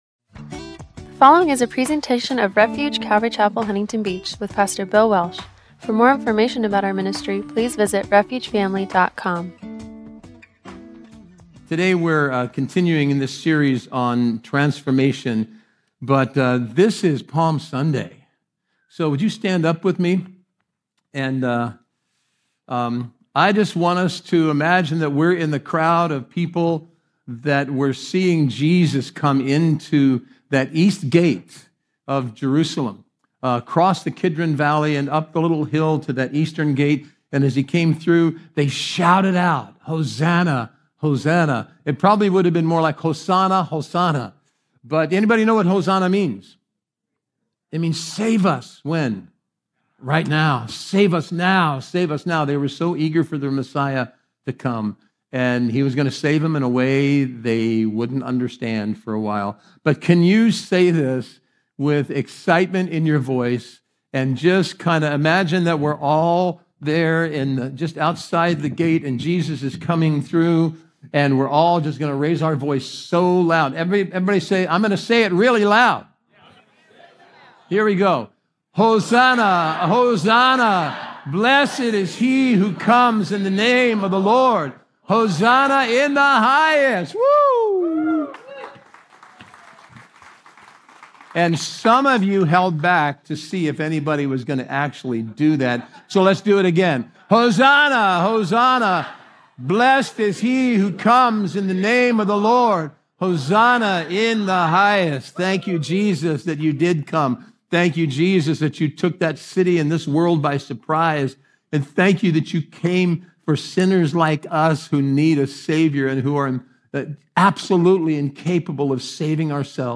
Service Type: Good Friday Service